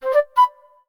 Samsung Galaxy Bildirim Sesleri - Dijital Eşik